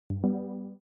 join_call-dEJCP2wD.mp3